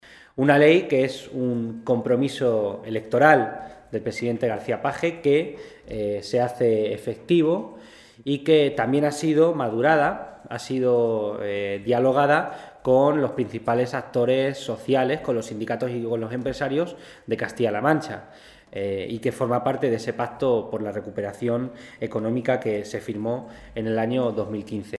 El diputado del Grupo socialista, Miguel González, ha asegurado que la Ley de Zonas Prioritarias que ha aprobado el Gobierno de Emiliano García-Page “va a ser una norma muy positiva que va a servir para equilibrar territorialmente nuestra región y va a fomentar sectores claves necesarios para el progreso de nuestra tierra”.
Cortes de audio de la rueda de prensa